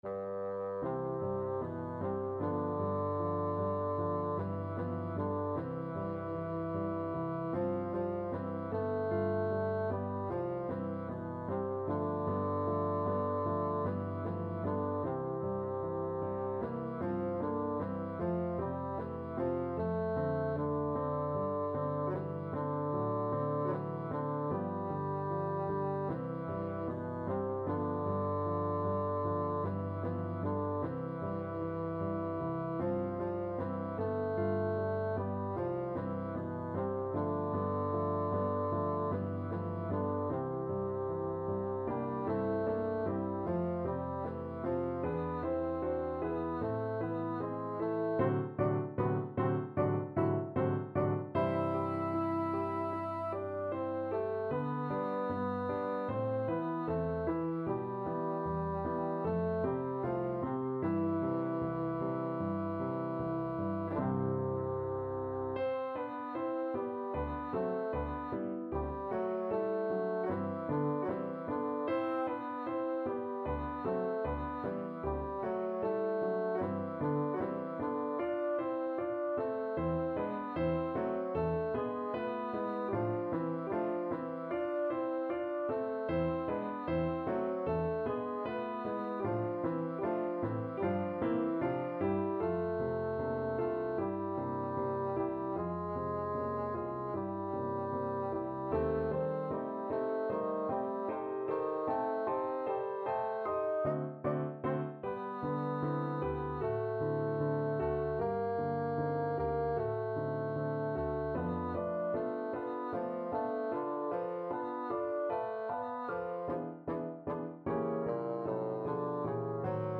Bassoon version
2/4 (View more 2/4 Music)
Lento ma non troppo = c.76
Classical (View more Classical Bassoon Music)